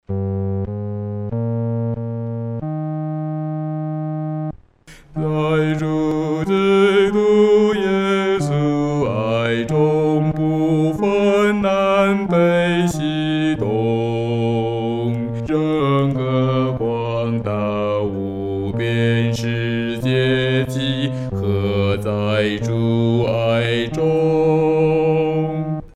独唱（第四声）